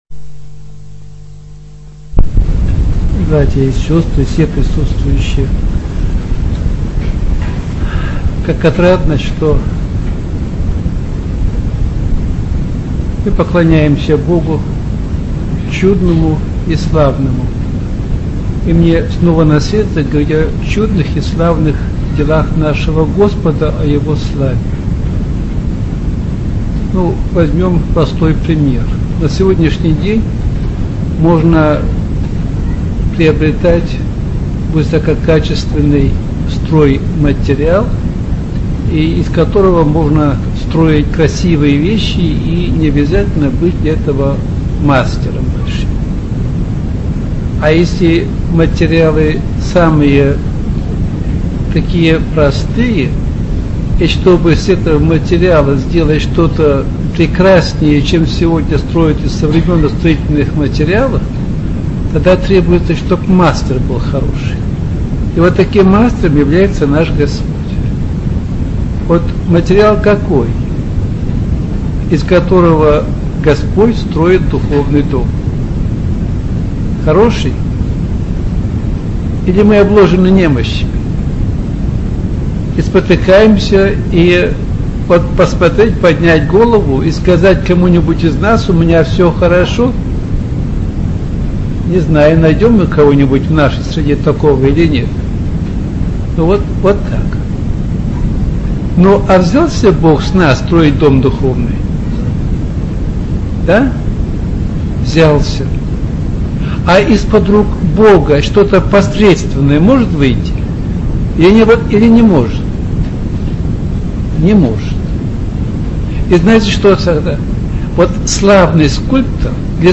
Аудио проповеди